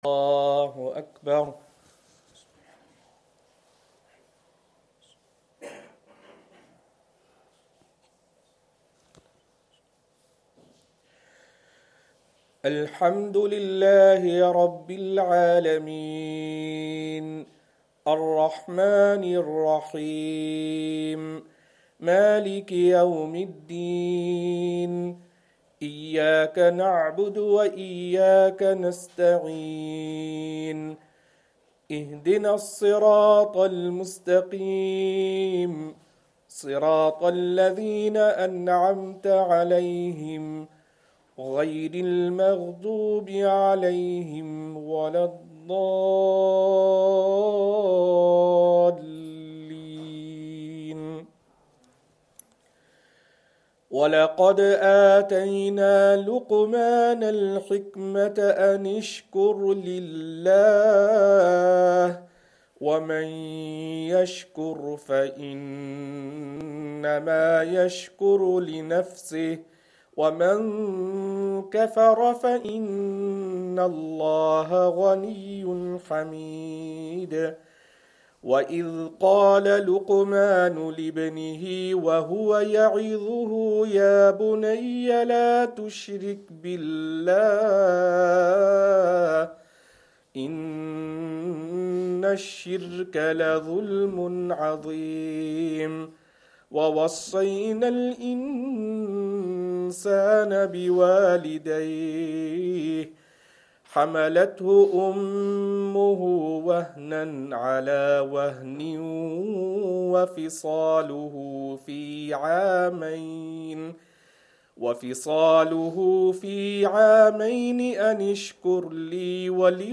Isha
Madni Masjid, Langside Road, Glasgow